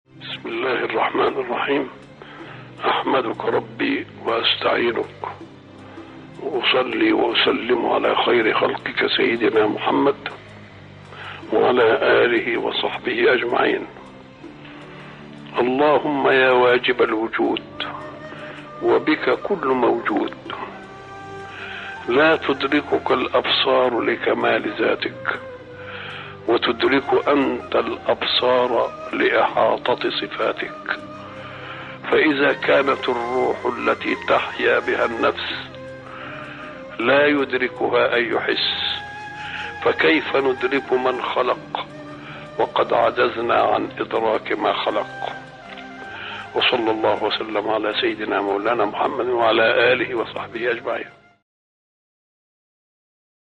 دعاء يبدأ بالثناء على الله والصلاة على النبي محمد، ثم يتأمل في عظمة الخالق وعجز المخلوق عن إدراك كنه ذاته سبحانه. النص يعزز الشعور بالأنس بالله من خلال التأمل في قدرته المطلقة.